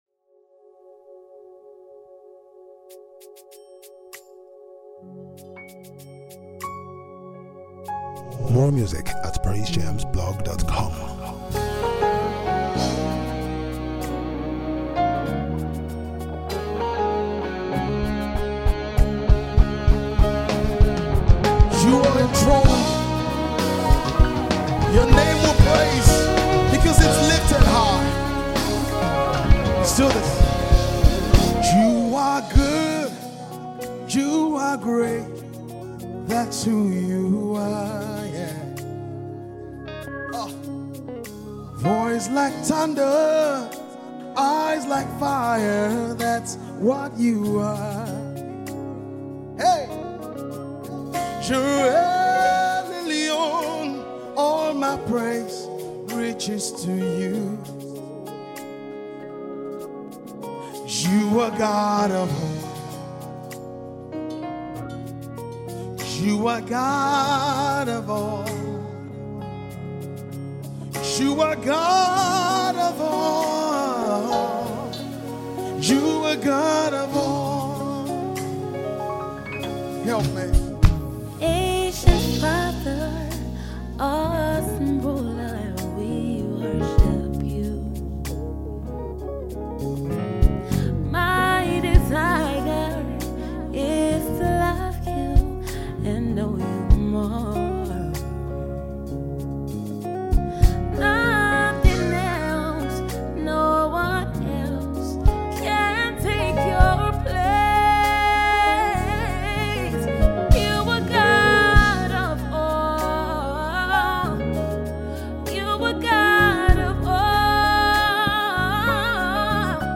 Nigerian gospel music minister and songwriter